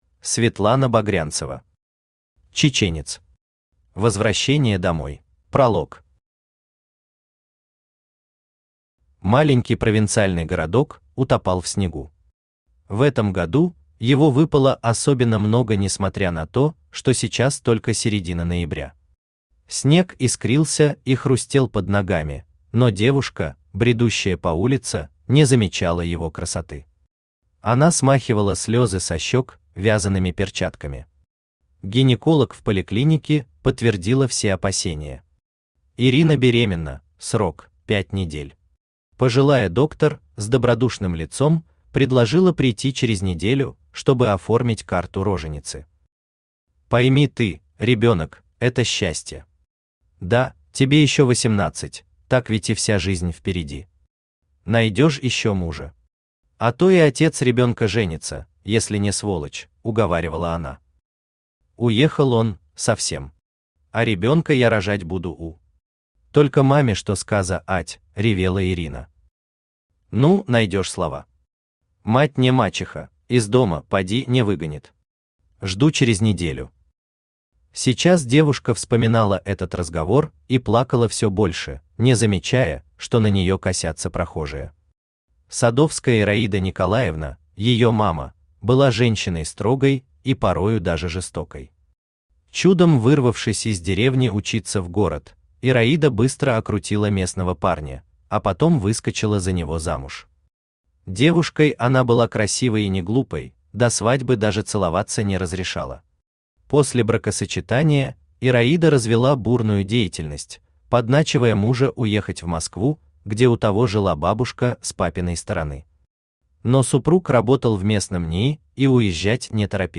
Аудиокнига Чеченец. Возвращение домой | Библиотека аудиокниг
Возвращение домой Автор Светлана Багрянцева Читает аудиокнигу Авточтец ЛитРес.